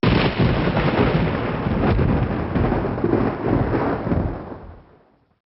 Boom Thunder Sound Effect Free Download
Boom Thunder